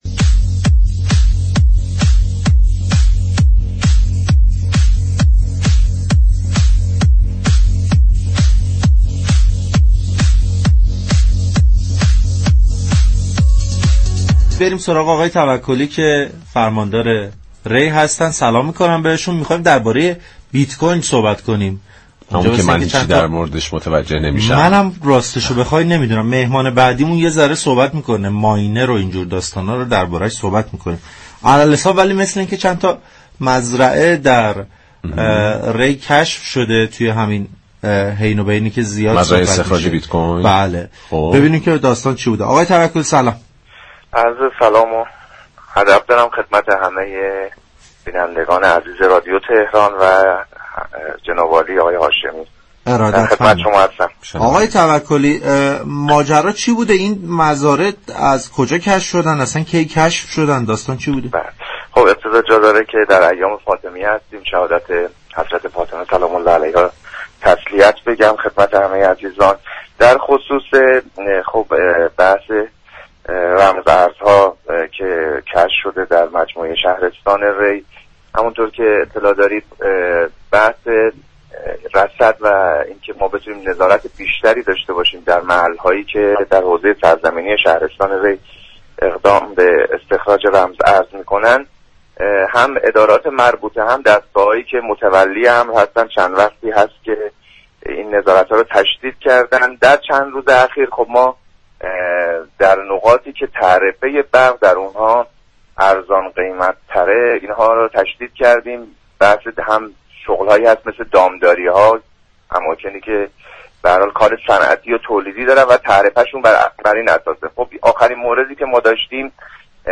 در ادامه گفتگوی برنامه پارك شهر 27 دیماه،